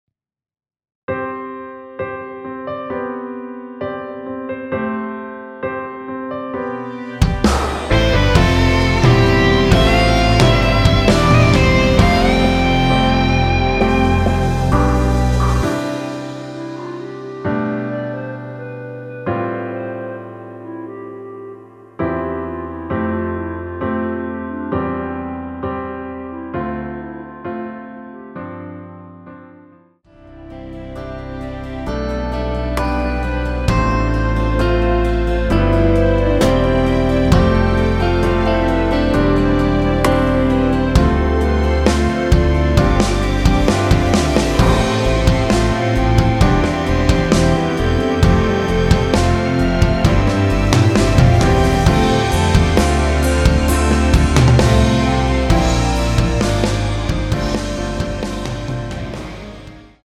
원키에서(-1)내린 (1절앞+후렴)으로 진행되는멜로디 포함된 MR입니다.(미리듣기 확인)
◈ 곡명 옆 (-1)은 반음 내림, (+1)은 반음 올림 입니다.
앞부분30초, 뒷부분30초씩 편집해서 올려 드리고 있습니다.
중간에 음이 끈어지고 다시 나오는 이유는